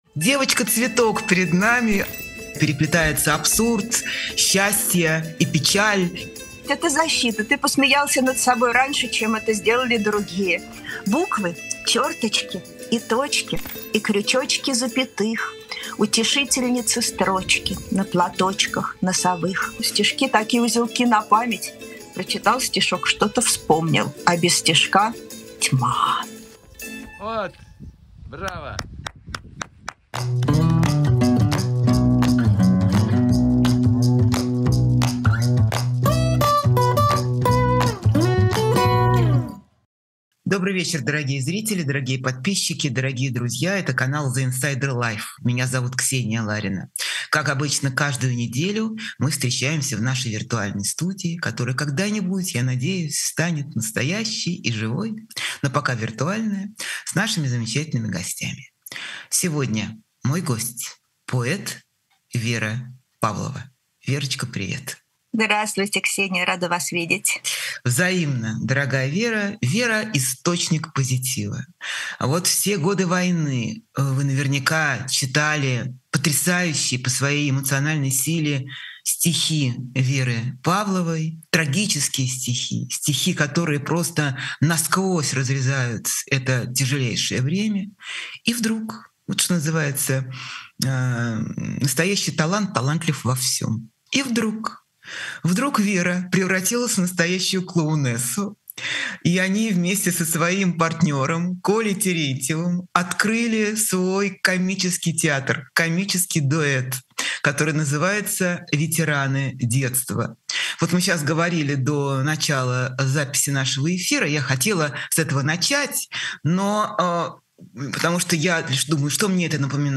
Эфир ведёт Ксения Ларина
Гость— поэтесса Вера Павлова.